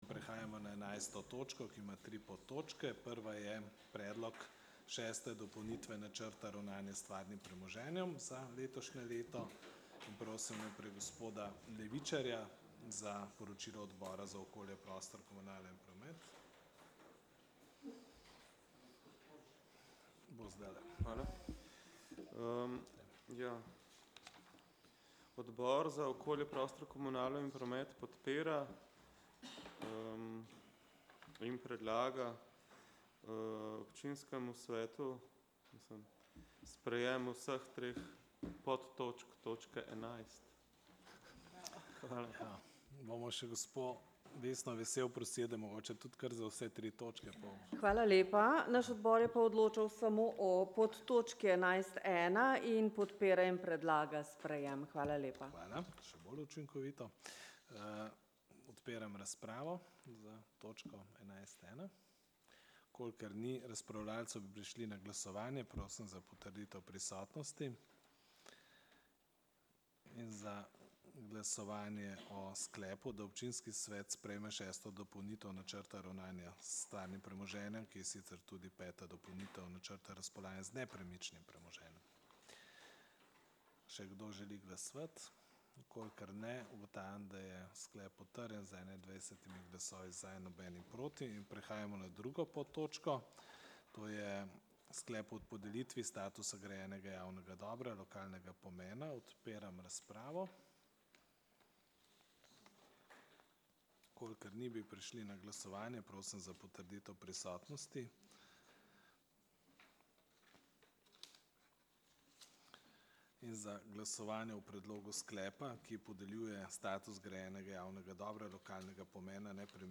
22. seja Občinskega sveta Mestne občine Novo mesto